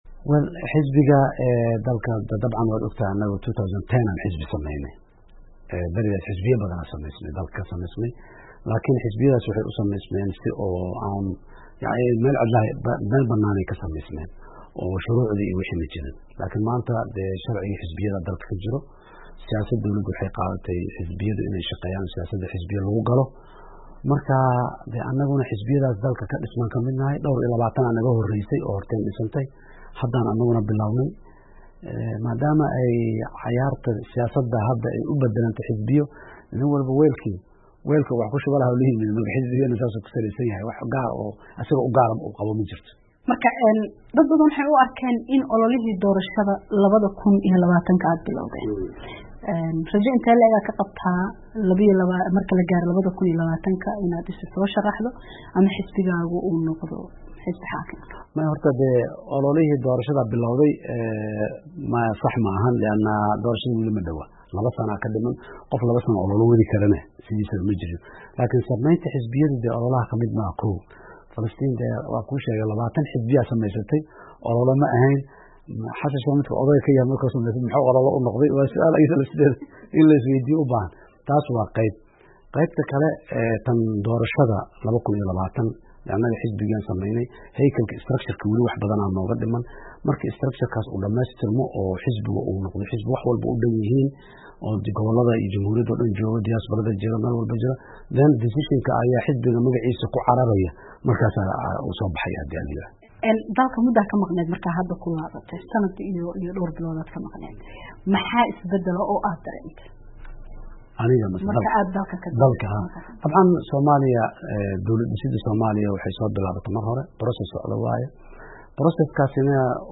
Wareysi: Madaxweyne Xasan Sheekh